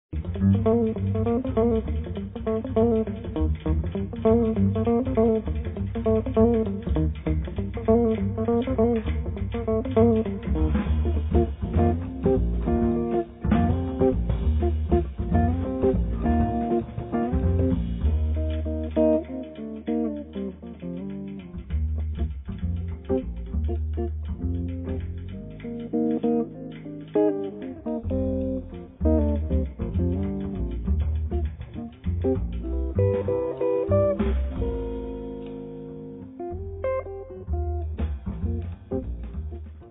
chitarra
contrabbasso
batteria
Un disco fatto di atmosfere evocative